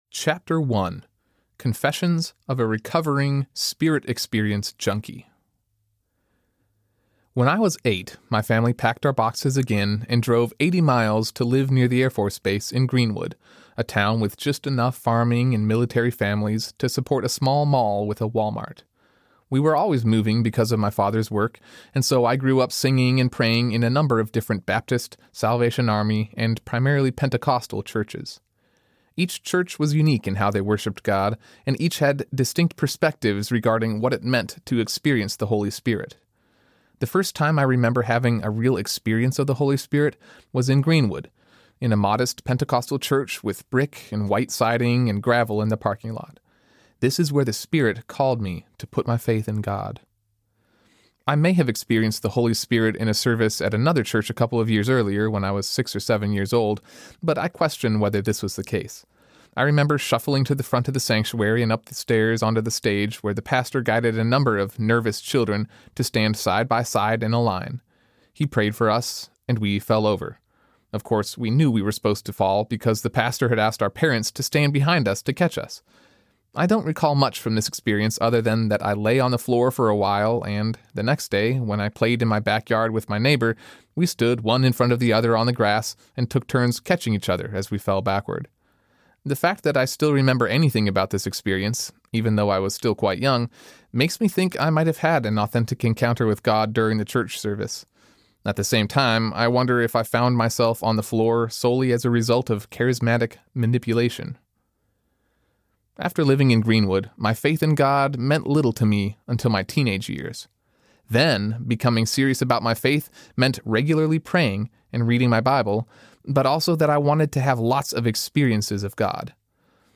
Simply Spirit-Filled Audiobook